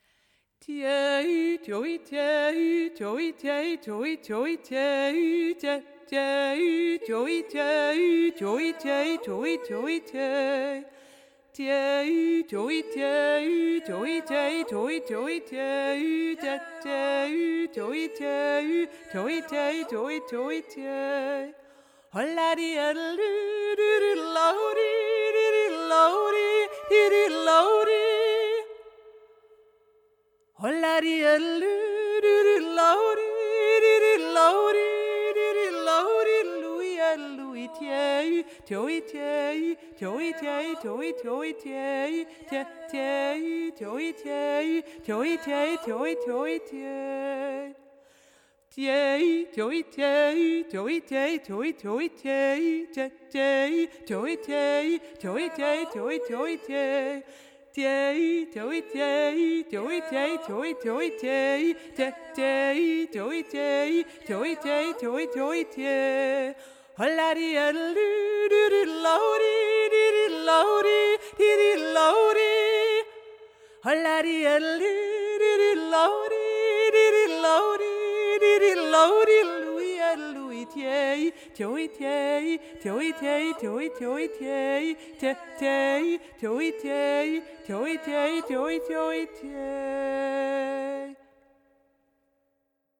pliss-hauptstimme-mp3.mp3